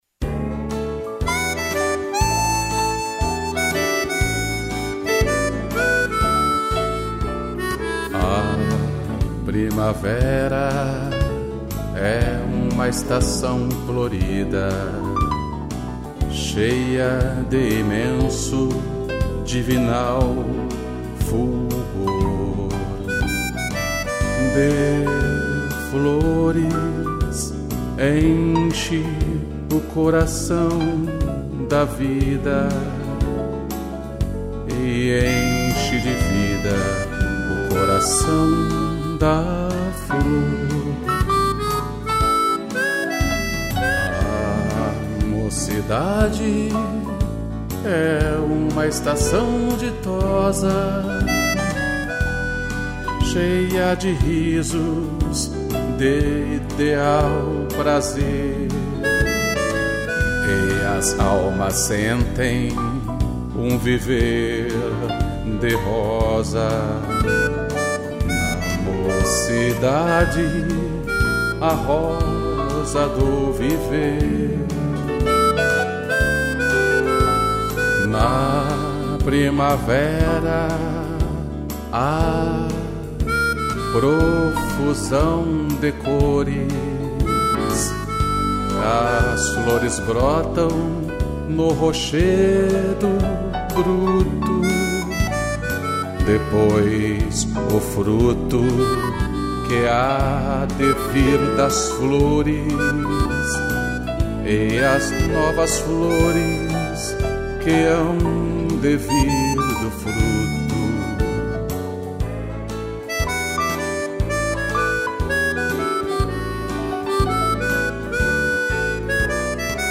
piano e gaita